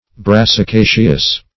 Search Result for " brassicaceous" : The Collaborative International Dictionary of English v.0.48: Brassicaceous \Bras`si*ca"ceous\, a. [L. brassica cabbage.]